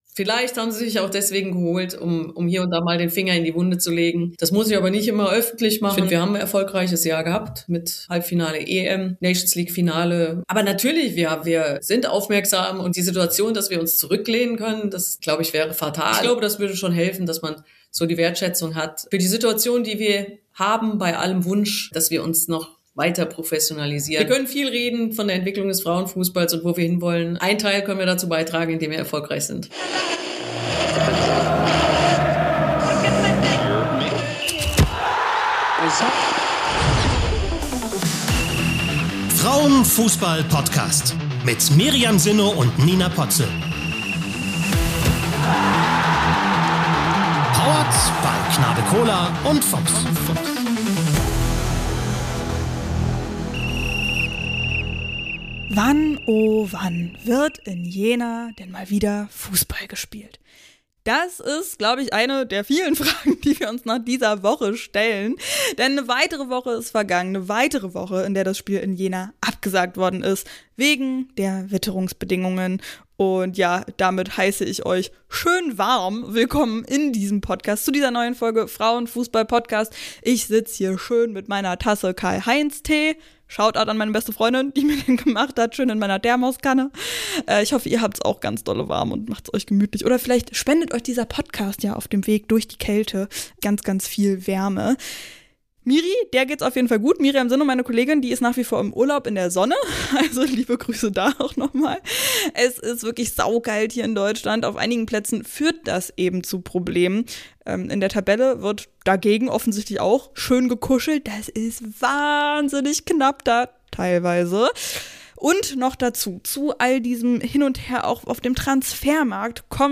Beschreibung vor 2 Monaten Perfekt zur Verlängerung des Bundestrainers ist die DFB-Sportdirektorin Nia Künzer bei uns zu Gast.